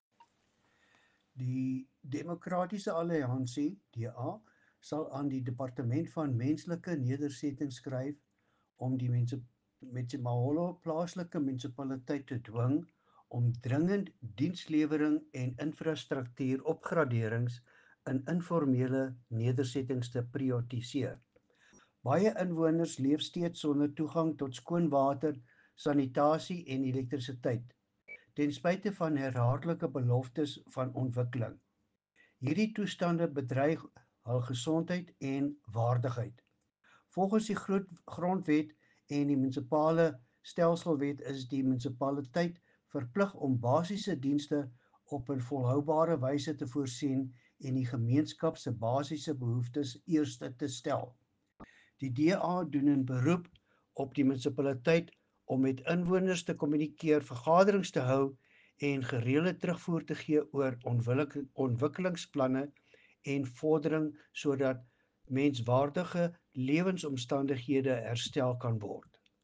Afrikaans soundbite by Cllr Louis van Heerden, and